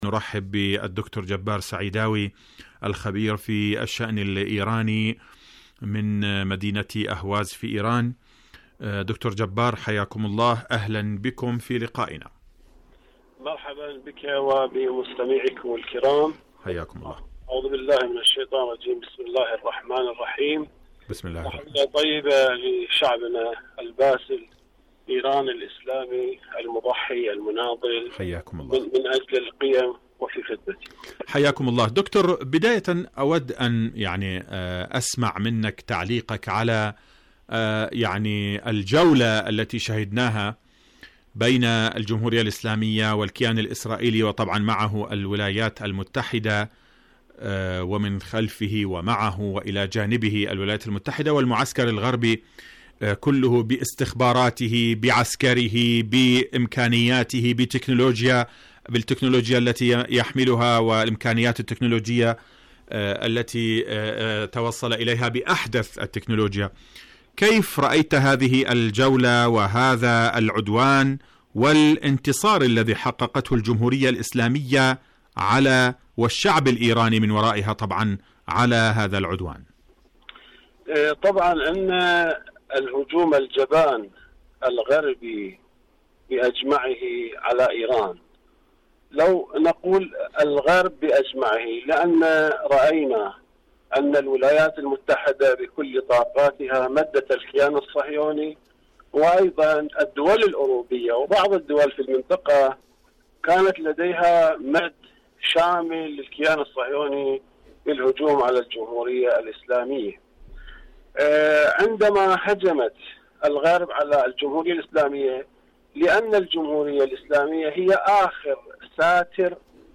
مقابلات برامج إذاعة طهران العربية برنامج حدث وحوار مقابلات إذاعية ترهات ترامب المقامر حرب نفسية بلا مصداقية العقوبات قاليباف حرب نفسية ترامب شاركوا هذا الخبر مع أصدقائكم ذات صلة المرأة الحسينية وأدوارها..